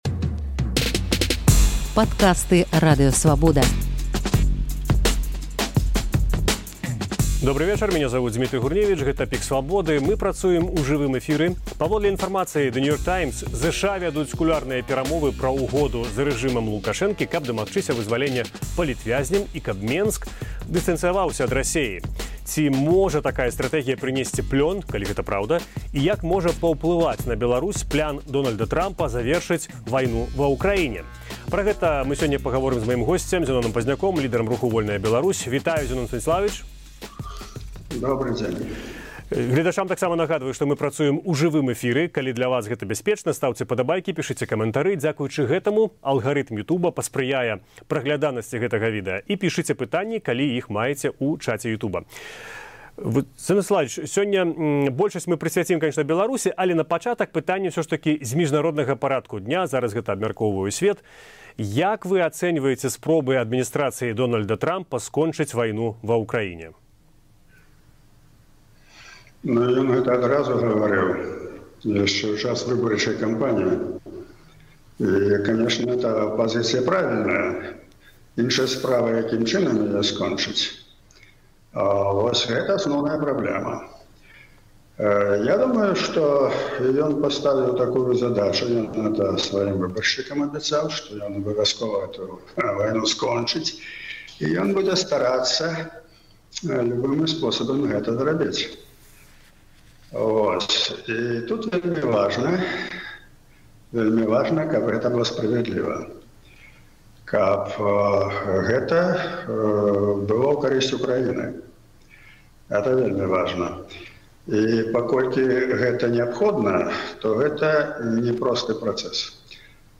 Лідэр руху «Вольная Беларусь» Зянон Пазьняк у жывым эфіры Свабоды